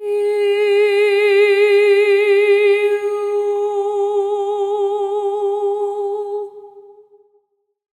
MZ Vox [Drill Soprano].wav